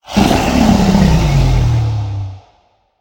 dragon_growl1.ogg